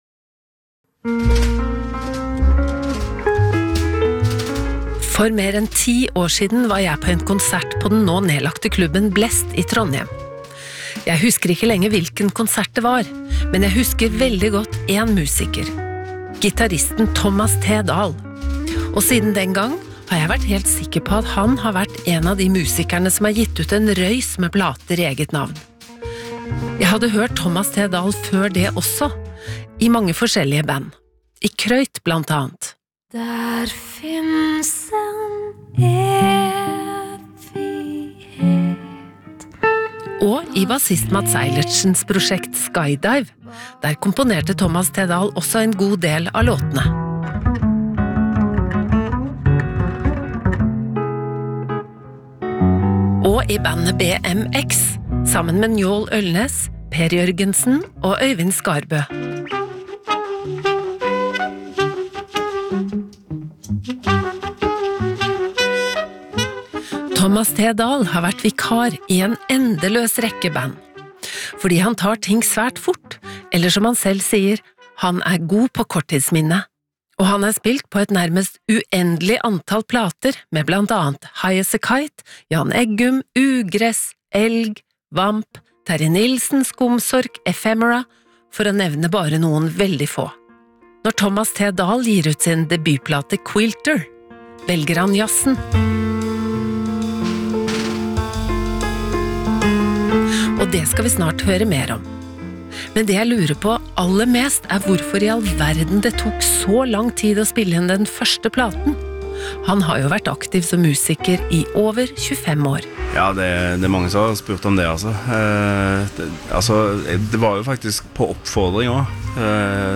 NRK P2 Interview